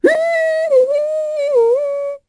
Pansirone-Vox_Hum_kr.wav